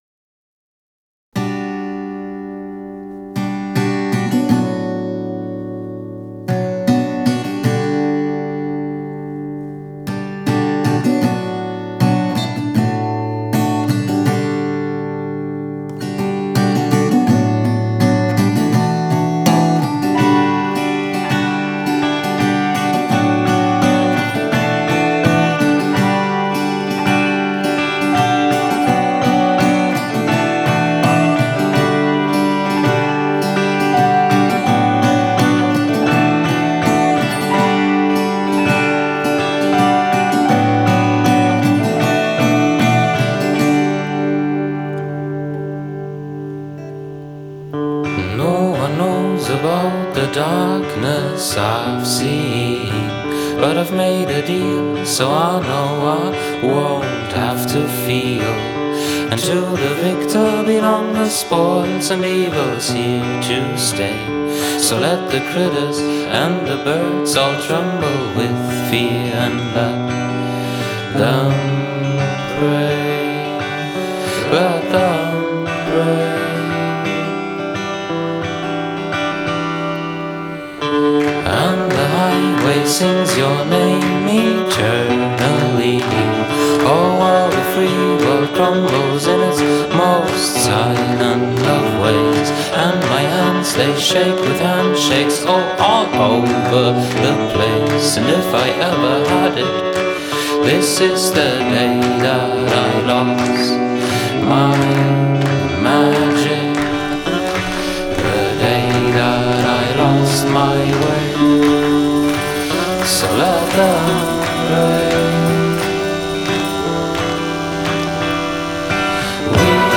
le duo